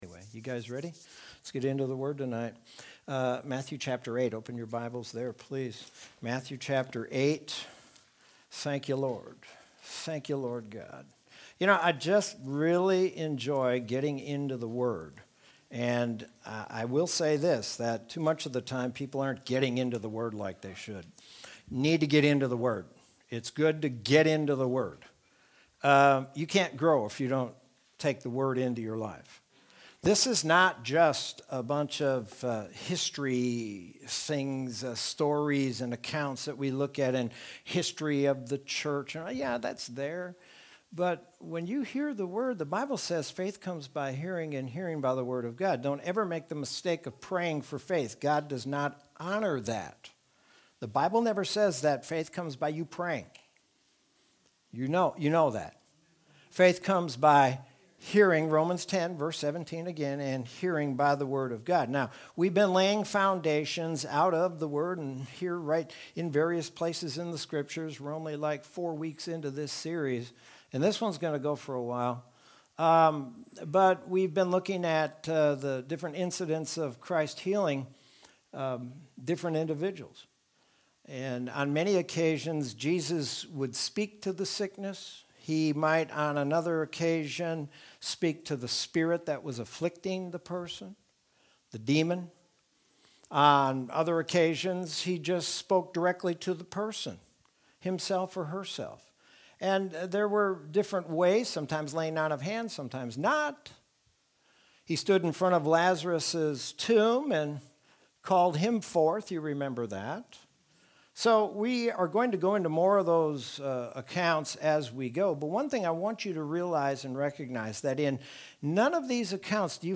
Sermon from Wednesday, January 27th, 2021.